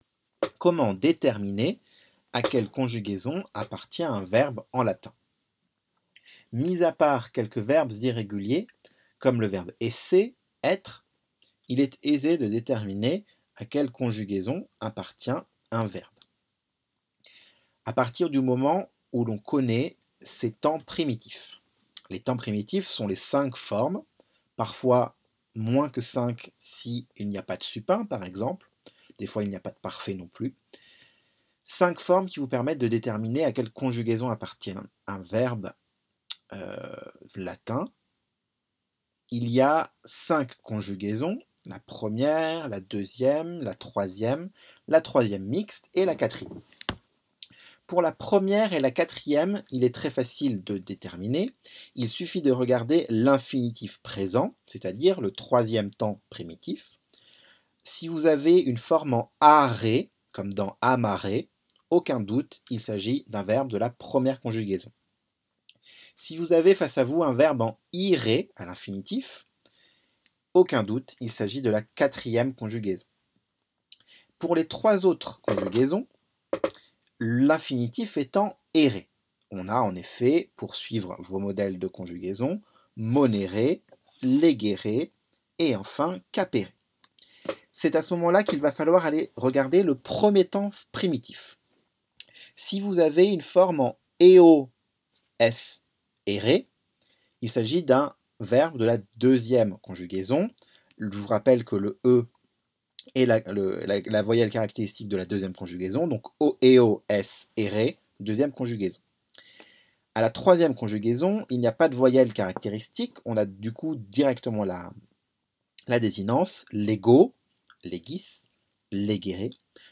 Mémos vocaux pour le latin
Pardon par avance pour les petites hésitations, erreurs de langues et autres zozotements ! =)